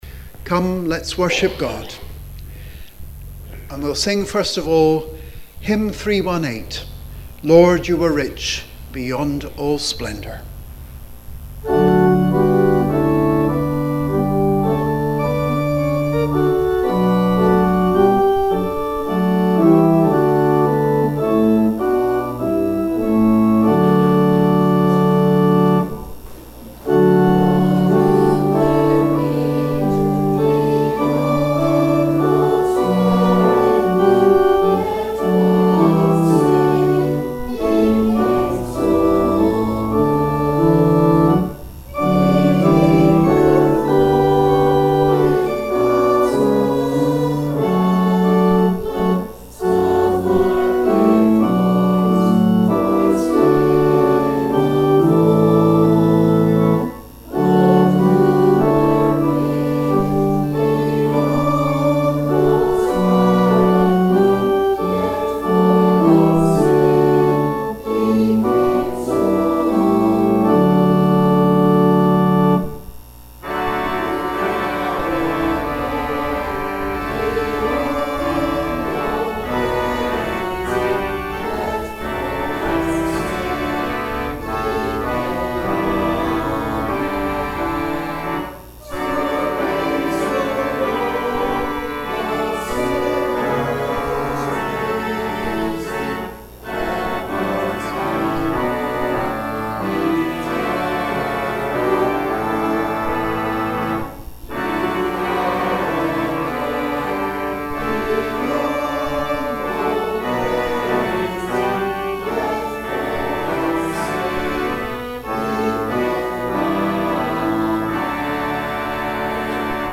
Christmas Eve at St. Mungo's - 24 December 2018
Watchnight Service and the celebration of Jesus birth
hymn 318 ‘Lord, you were rich beyond all splendour’.